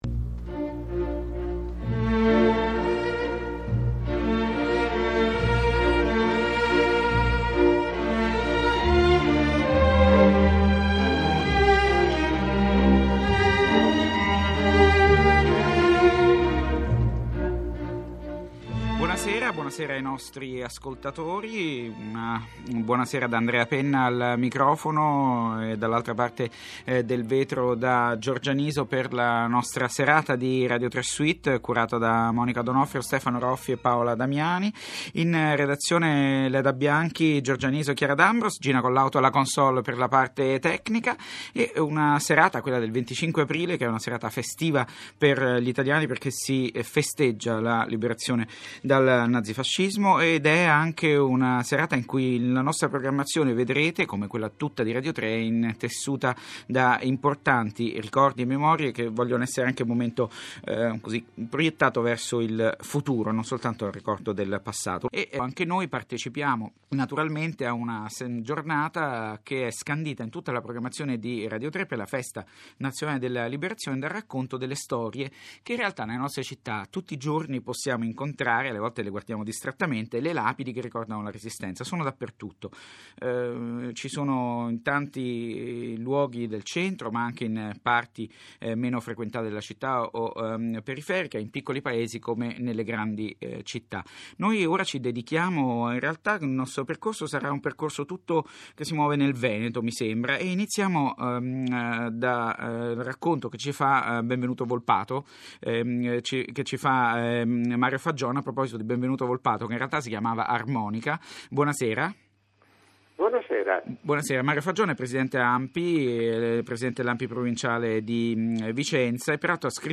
Radio3 Suite ha trasmesso uno speciale per il 25 Aprile
Il 25 Aprile su Radio3 è andata in onda una puntata speciale di Radio3 Suite che ha parlato di tre lapidi presenti sul territorio vicentino, legate a tre episodi della Resistenza.